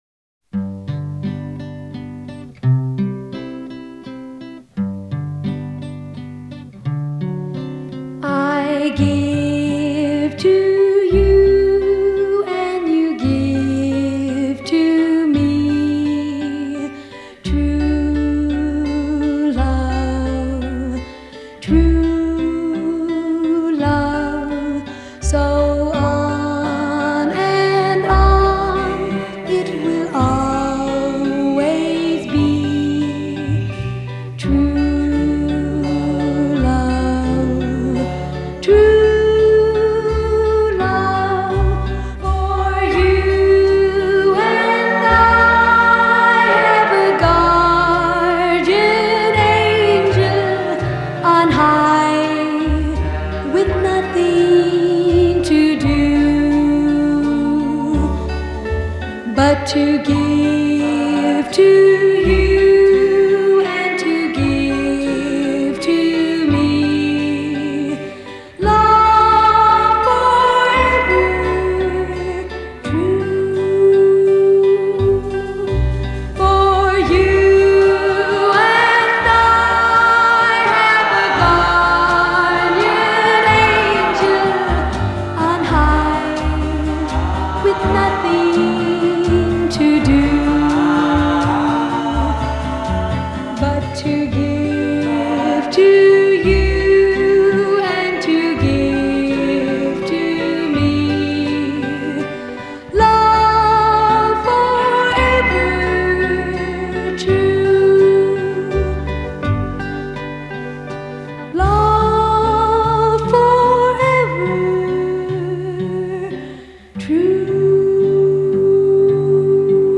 Жанр: Pop, Oldies, Doo-Woop
lead vocals